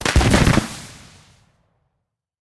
smoke_fire.ogg